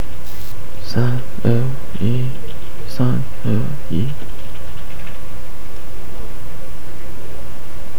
mono48KHz 是从左麦克风录制的 wav 文件。
我使用一个麦克风来录制我的声音、方法是立即插入左右麦克风、使其变得非常安静。
mono48KHz_5F00_Lmic.wav